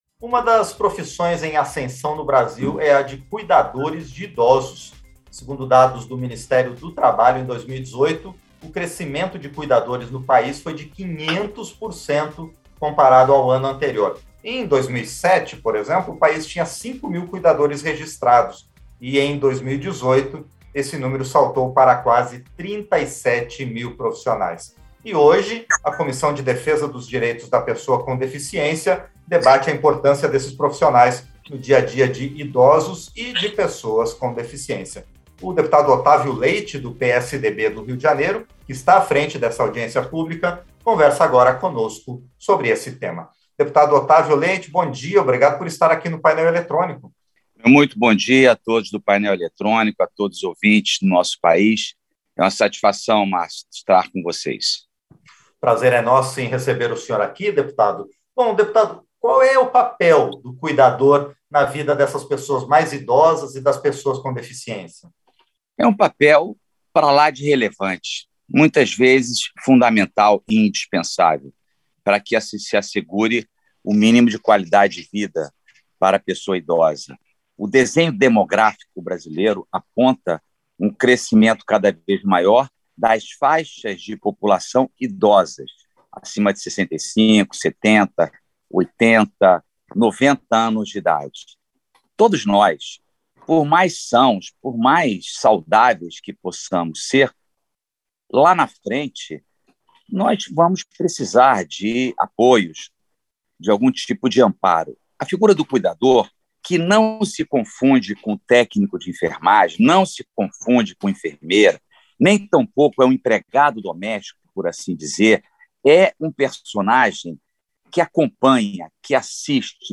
Entrevista - Dep. Otávio Leite (PSDB-RJ)